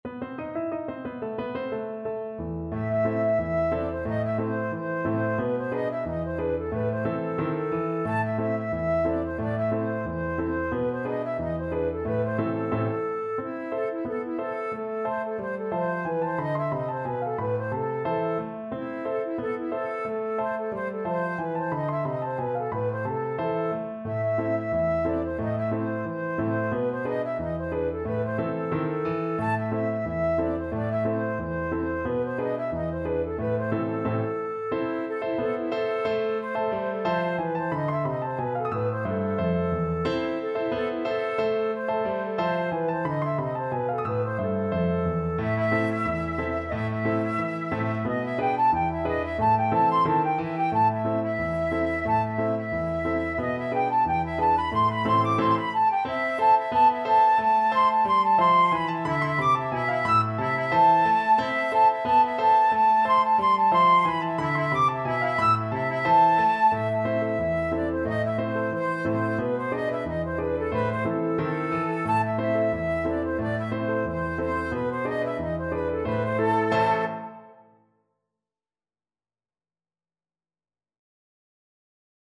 FluteFlute
Traditional Music of unknown author.
A minor (Sounding Pitch) (View more A minor Music for Flute )
2/4 (View more 2/4 Music)
With energy =c.90
World (View more World Flute Music)